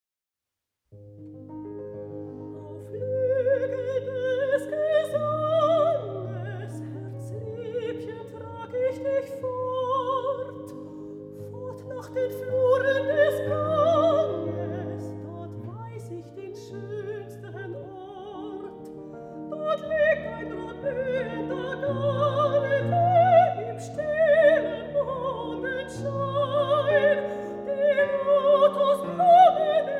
Durs Grünbein - Sprecher